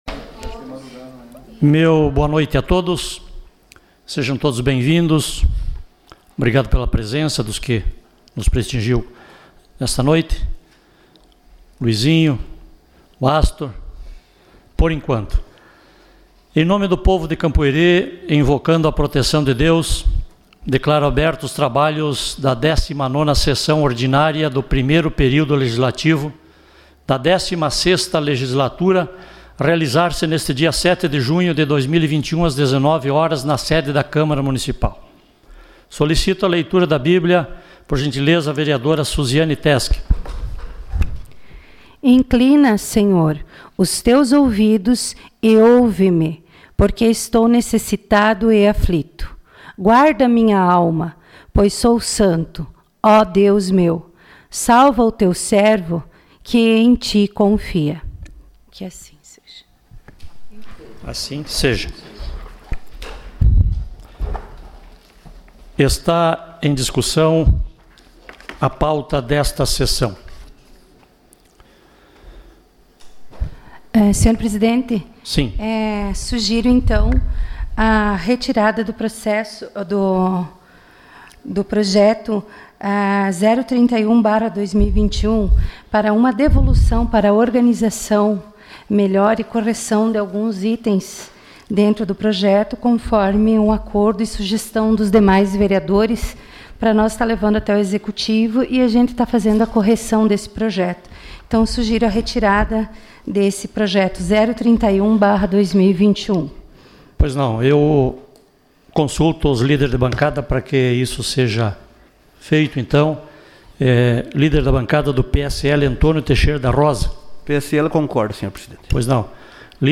Sessão Ordinária dia 07 de junho de 2021